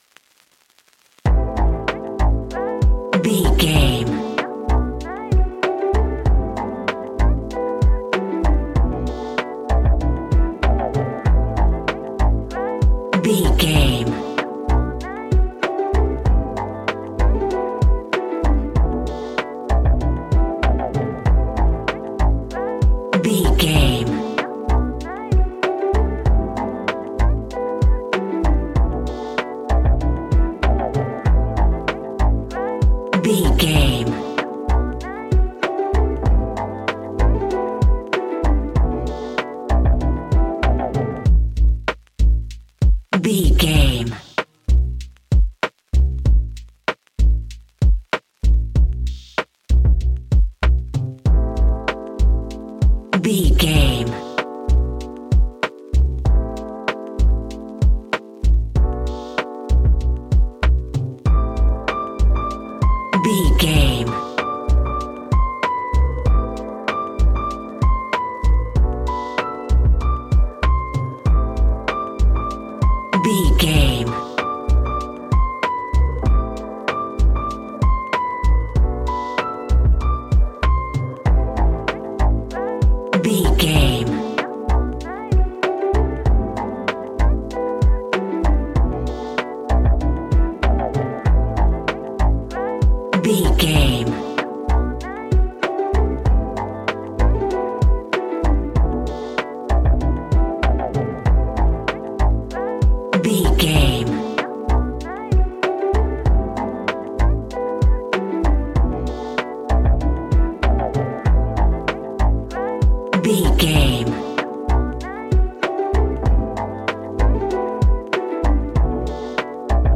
Ionian/Major
A♭
chilled
laid back
Lounge
sparse
new age
chilled electronica
ambient
atmospheric
instrumentals